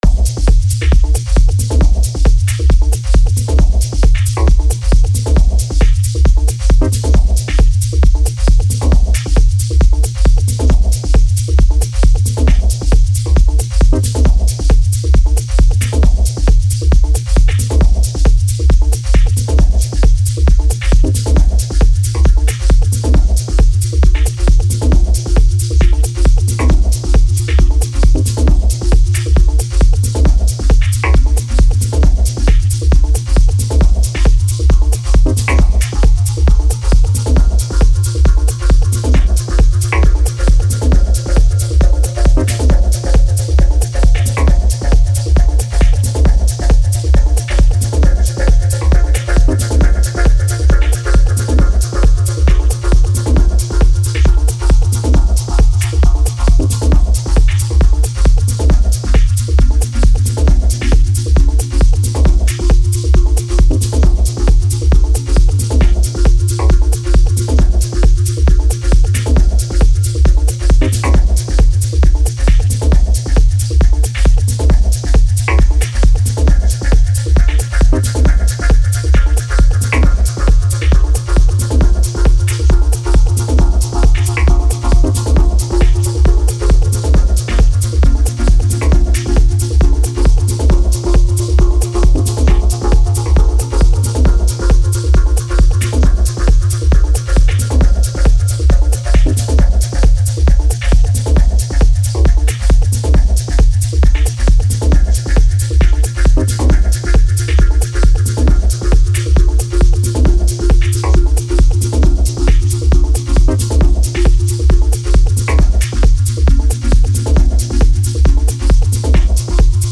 Best synth for dub chords (dub techno)
Here’s a simple pattern ran through a bunch of Eurorack filters and fx.
The fm really comes alive when combined with external modulation.
Drums/perc by some modules and Deluge
Bass by BIA
Awesome bass, lovely filtered chords.